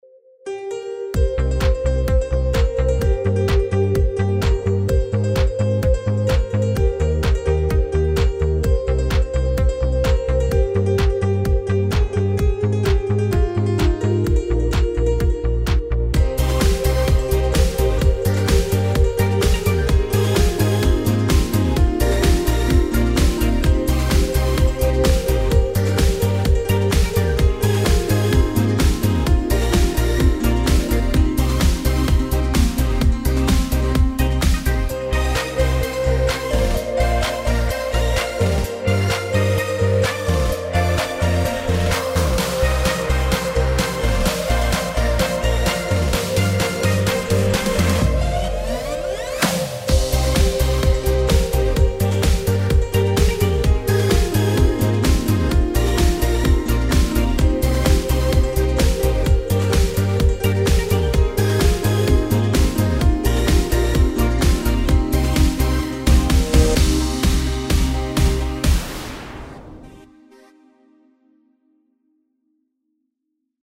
Piano trifft auf heißen Tanzbeat.